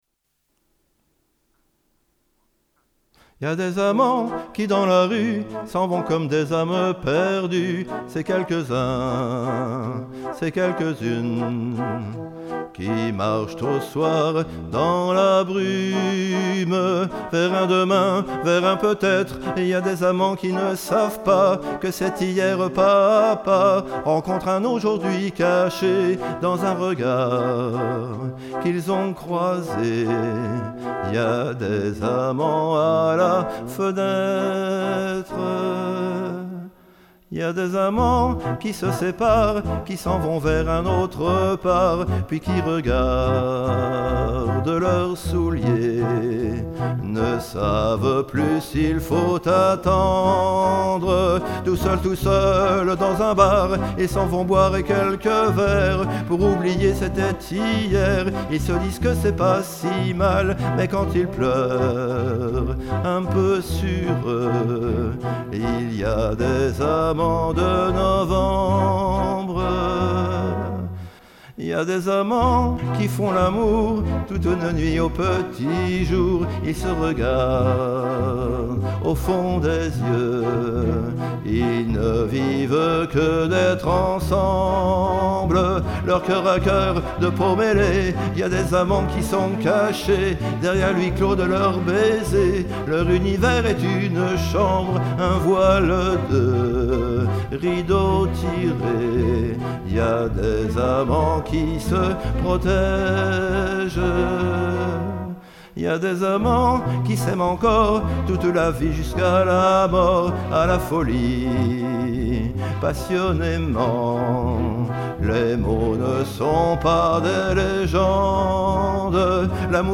Musique, chant, guitare